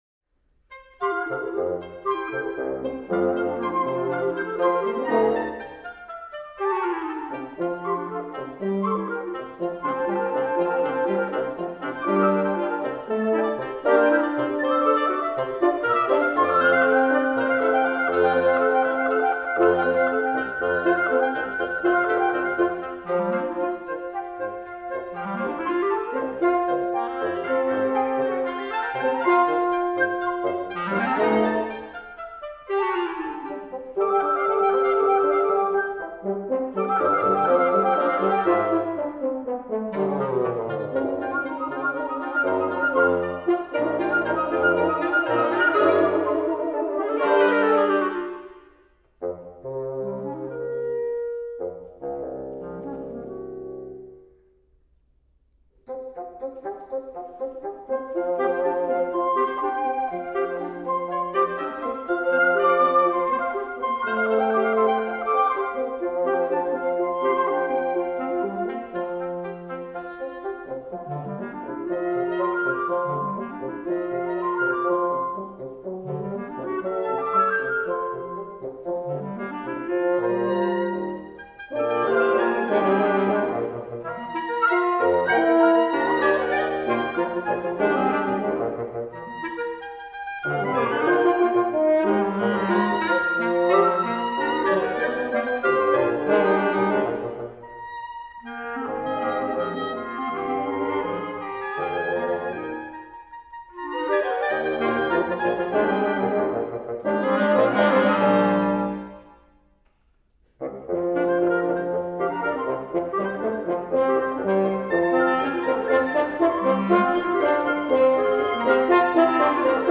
From “Wind Quintet on Swiss Folk Songs, op. 53 (MWV 282, 1941)”